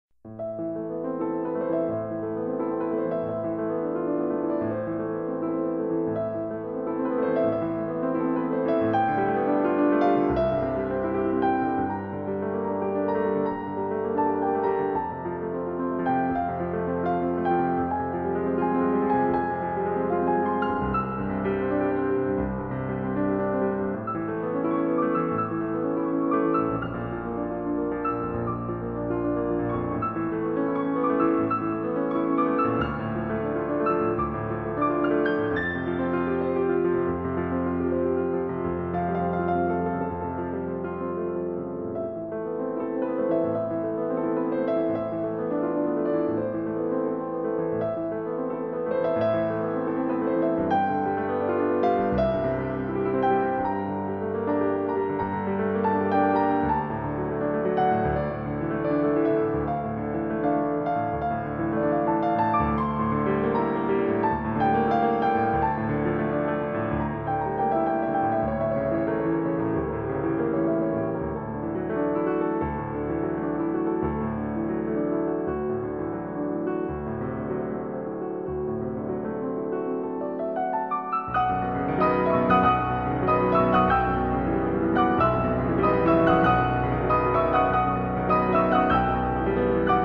piano collection